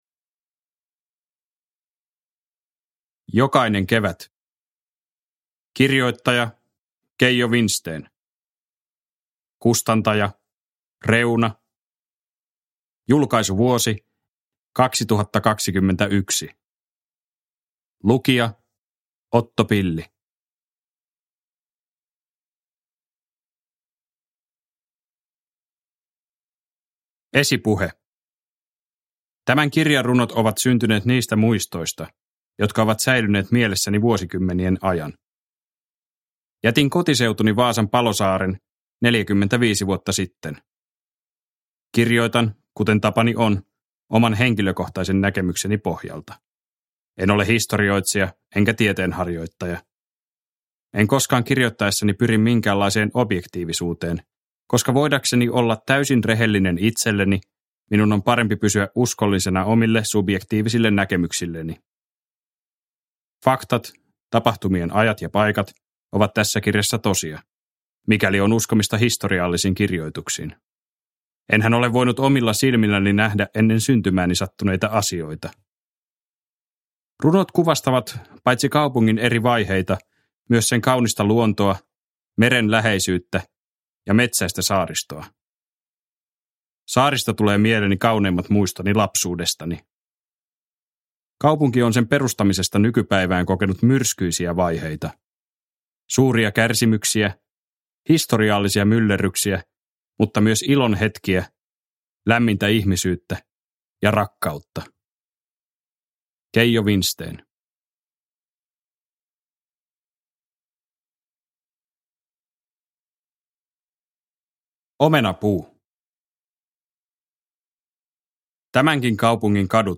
Jokainen kevät – Ljudbok – Laddas ner